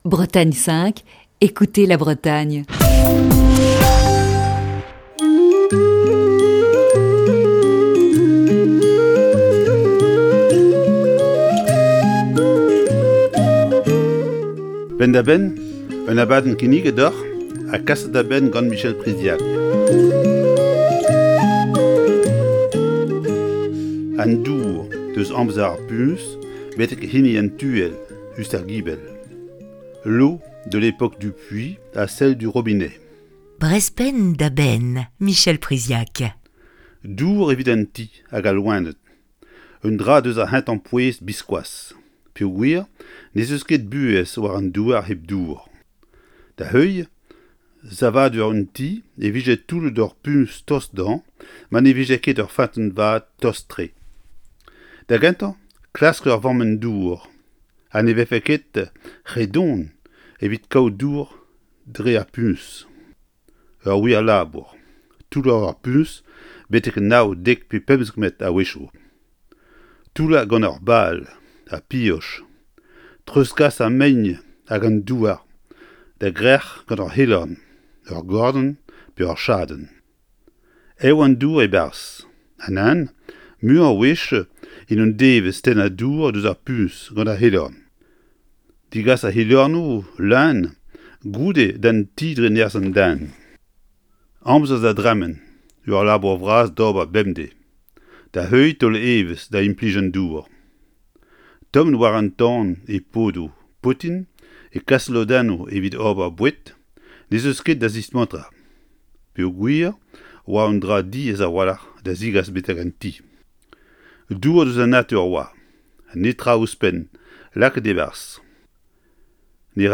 Chronique du 2 mars 2020. Aujourd'hui, un simple geste et l'eau jaillit du mitigeur ou du robinet, mais jadis à une époque où l'adduction d'eau n'était pas réalisée, en particulier à la campagne, il fallait aller chercher l'eau au puits.